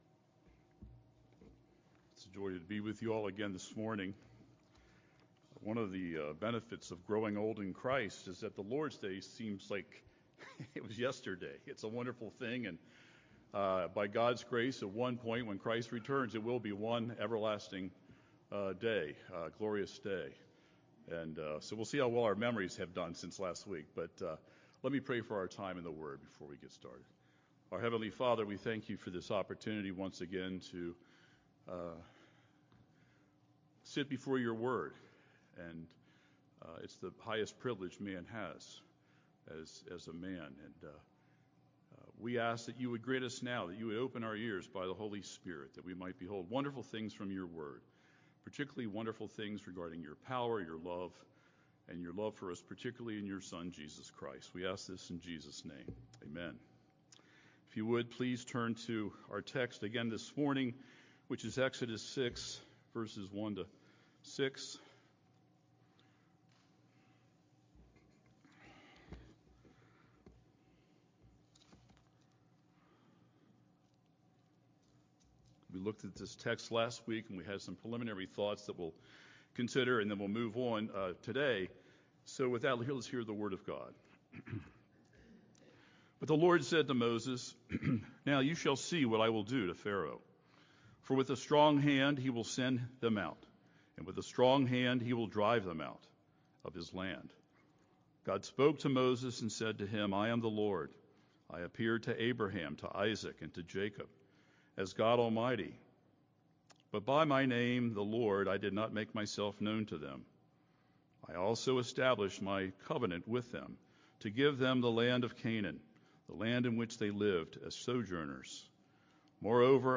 God’s Timing is Everything: Sermon on Exodus 6:1-9